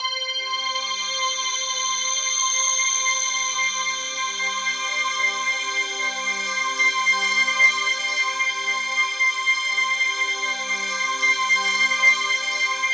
TUBULARC5.-L.wav